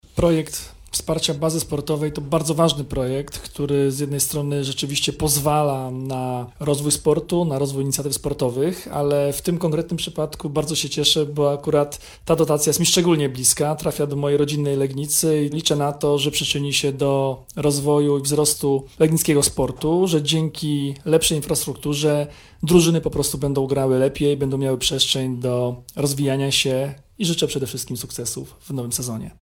– Liczę, że ta dotacja przyczyni się do rozwoju legnickiego sportu, mówi Jarosław Rabczenko – Członek Zarządu Województwa Dolnośląskiego.